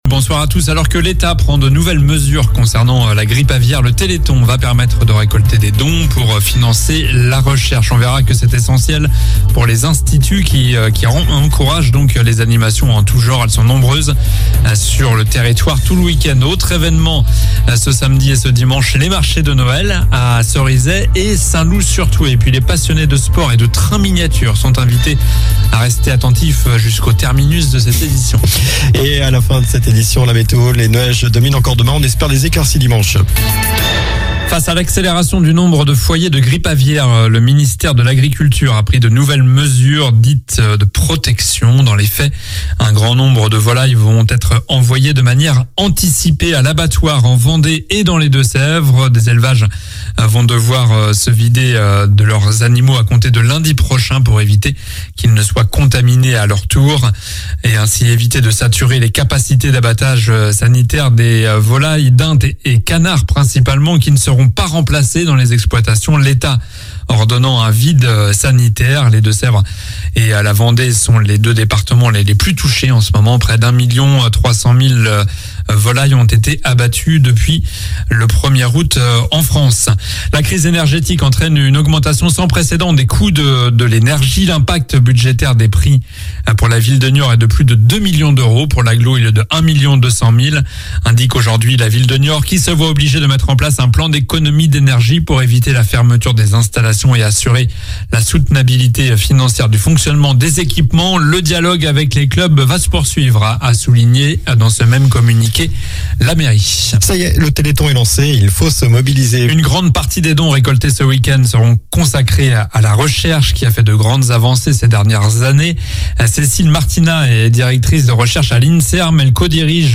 Journal du vendredi 02 décembre (soir)